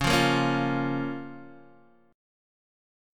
C#6 chord